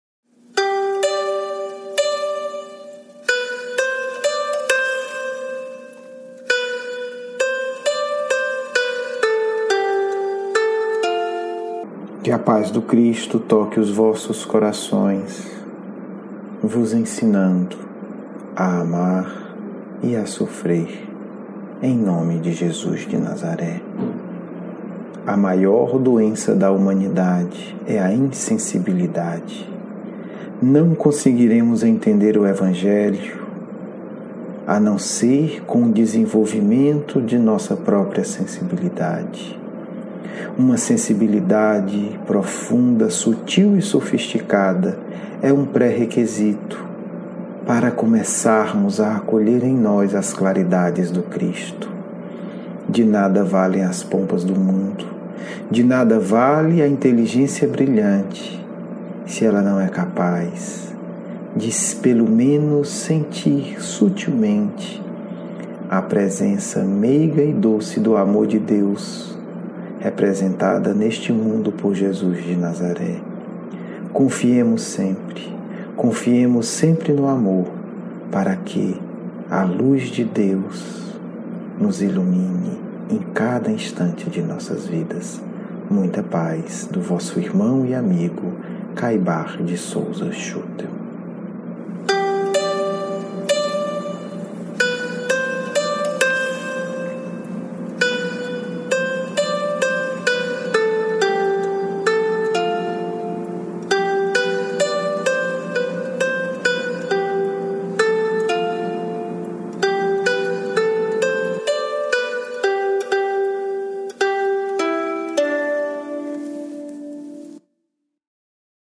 Diálogo mediúnico